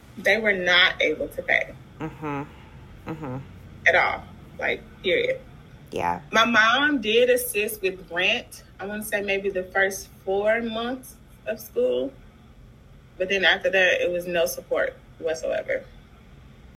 Demographics: Black woman, 38 (Millennial)[22], first-generation college student, raised in single-parent household